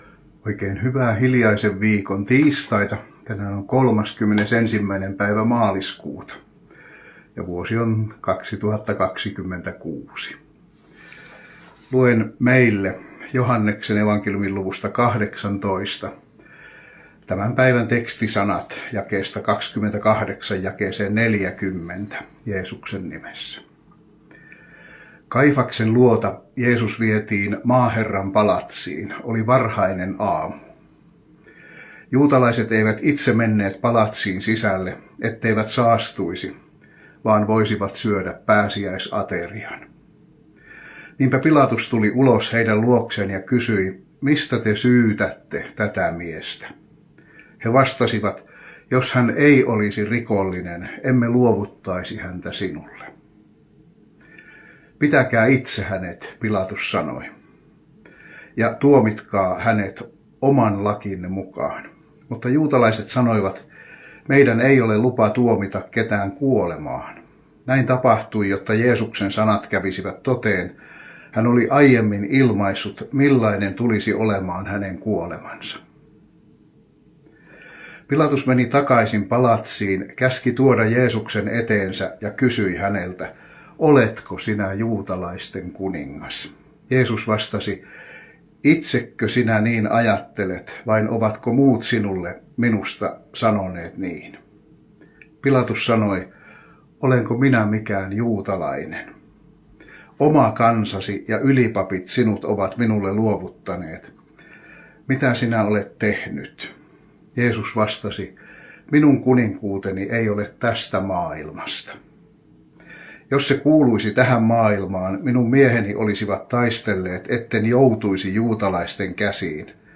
Tekstinä Joh. 18: 28-40, äänenlaatu heikkenee hieman 6 minuutin jälkeen--